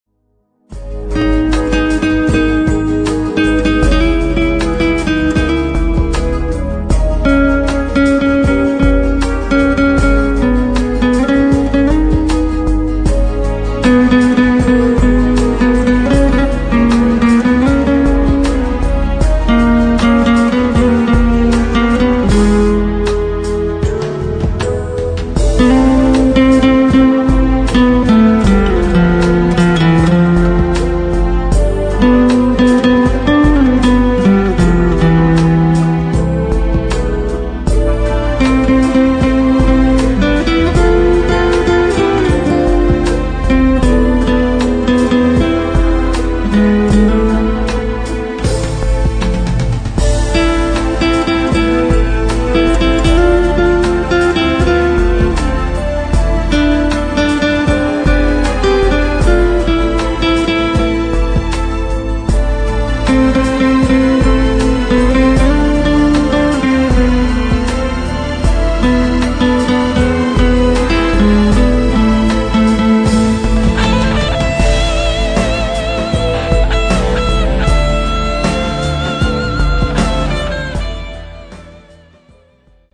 guitare accoustique